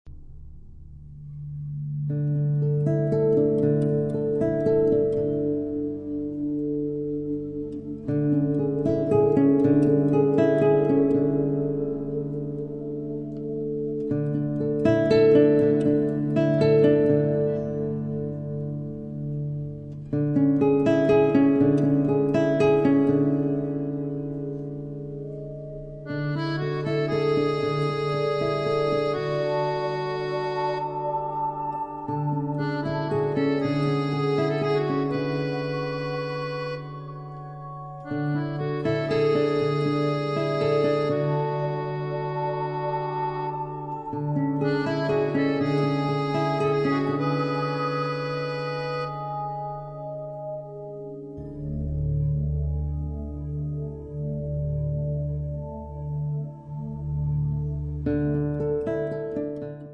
Chitarre, oud, basso
Un percorso impregnato di Mediterraneo